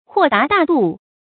豁達大度 注音： ㄏㄨㄛˋ ㄉㄚˊ ㄉㄚˋ ㄉㄨˋ 讀音讀法： 意思解釋： 豁達：開朗；大度：氣量大。